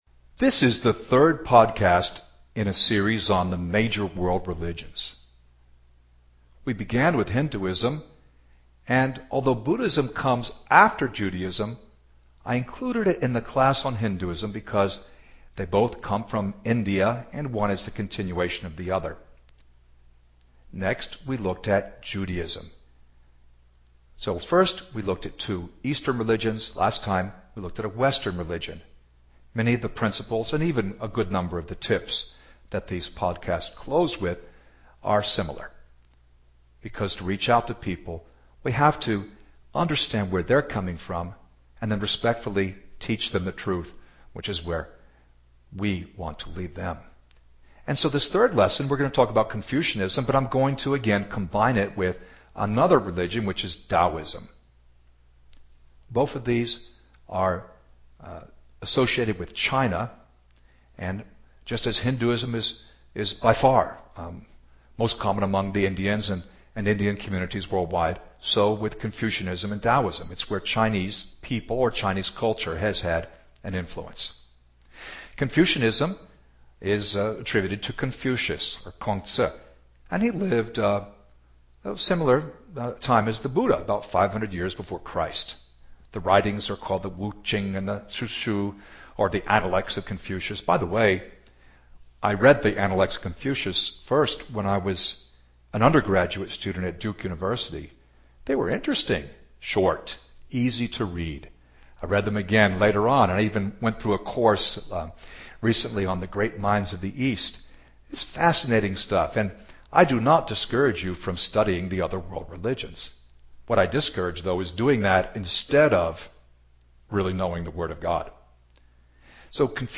LESSON 3: CONFUCIANISM & TAOISM